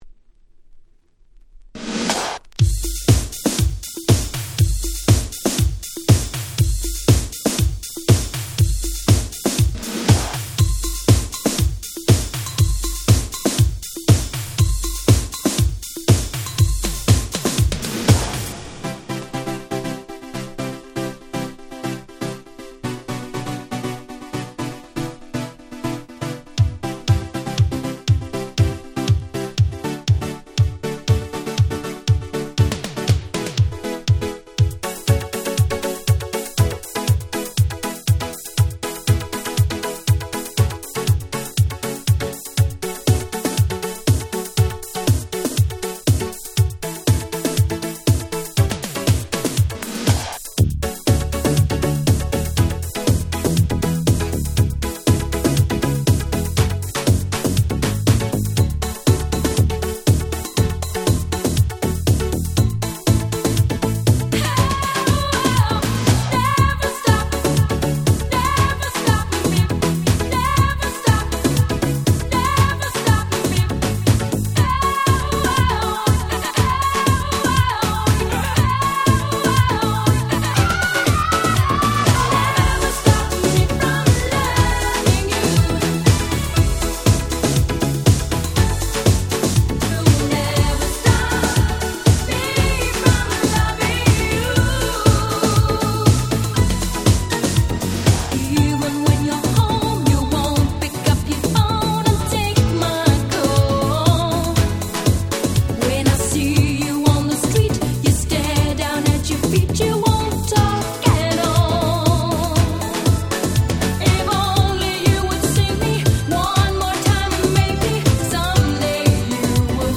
89' Big Hit Disco / Euro Beat !!
80's ユーロビート